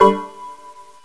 chord.wav